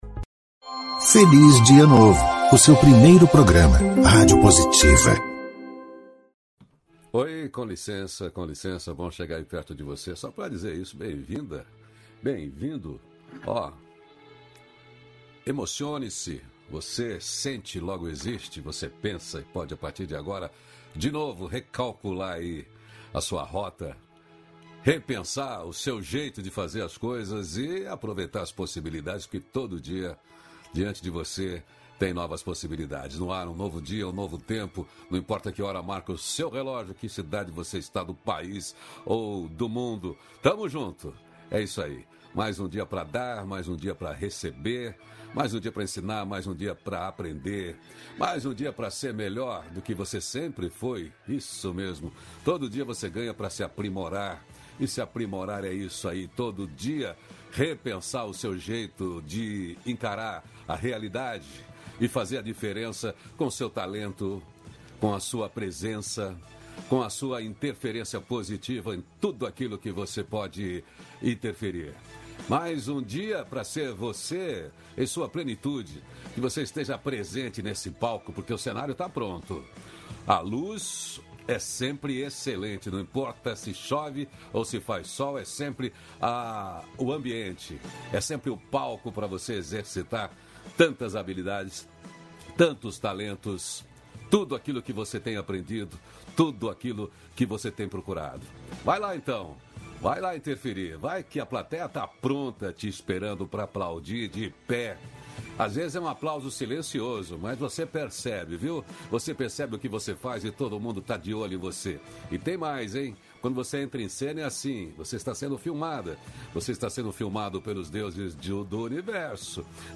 O dialogo nutritivo de hoje vai ser com o ator de teatro, televisão, cinema.
-554FelizDiaNovo-Entrevista.mp3